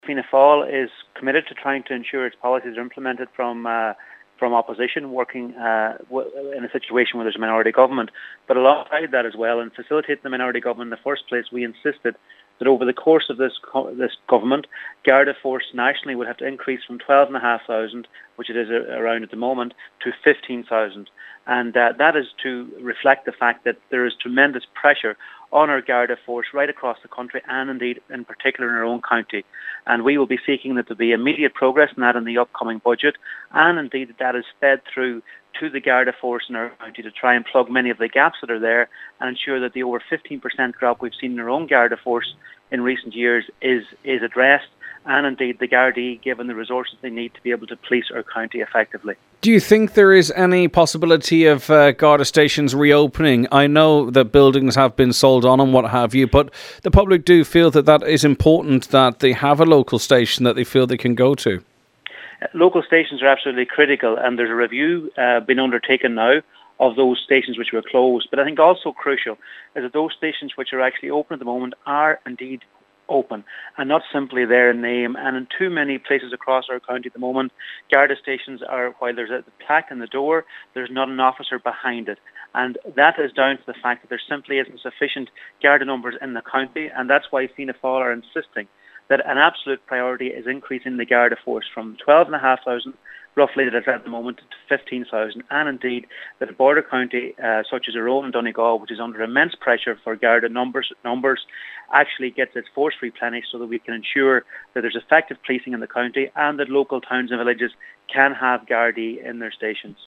Eight Garda stations closed under Fine Gael and Labour and Garda numbers have been cut substantially – Deputy McConalogue says has had an impact not only on crime rates, but also on people’s confidence levels: